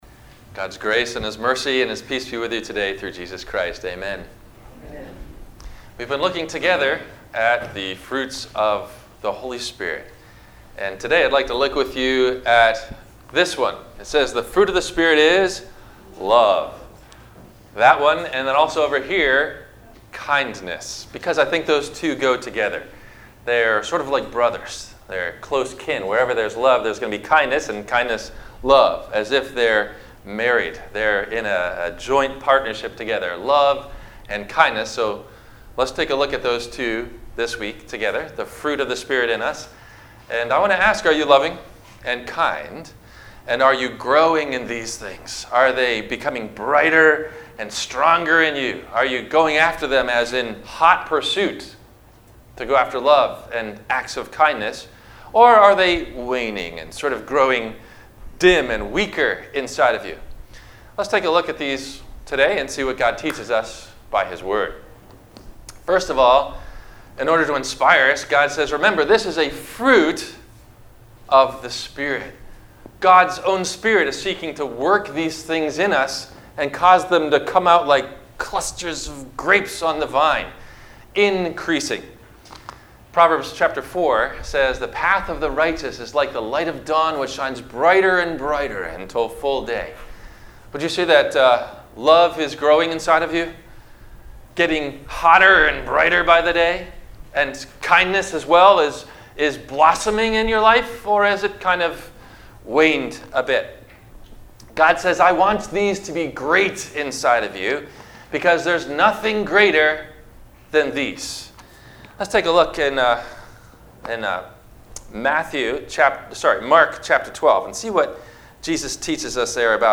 - Sermon - November 03 2019 - Christ Lutheran Cape Canaveral